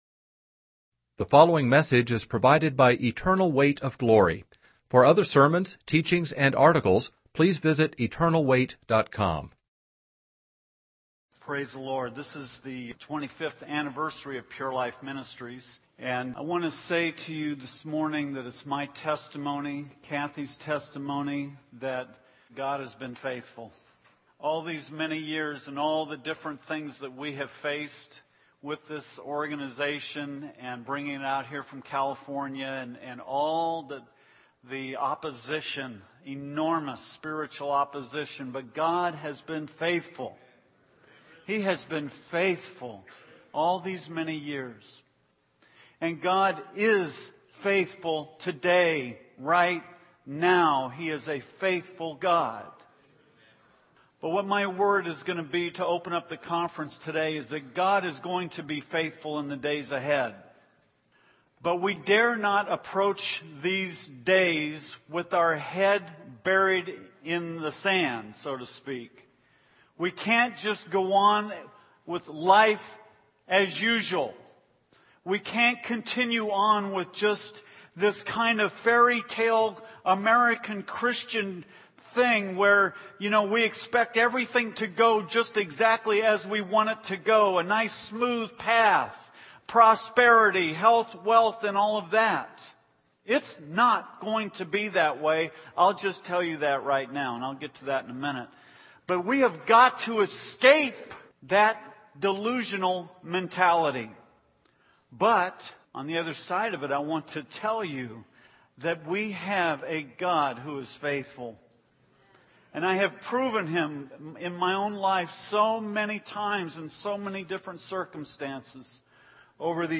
In this sermon, the speaker discusses the signs of the end times as described by Jesus in Matthew 24. He emphasizes the importance of enduring until the end to be saved.